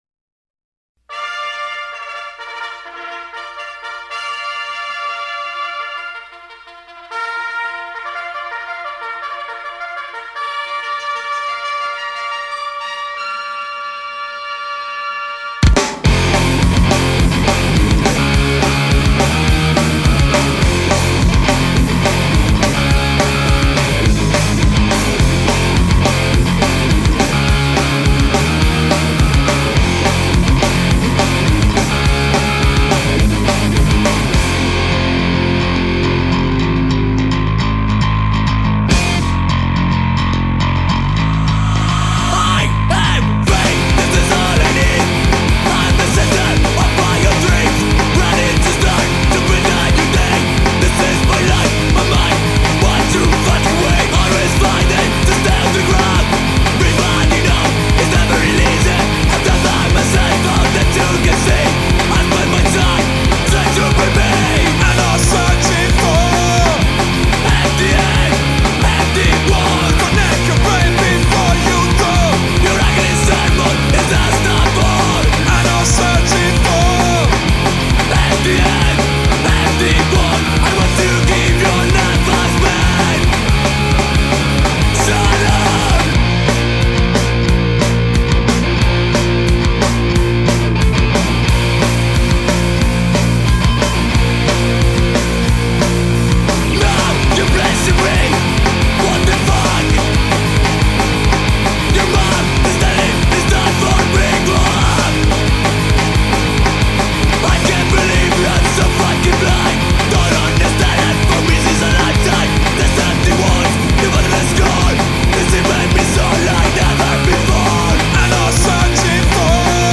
Genere: punk / rock /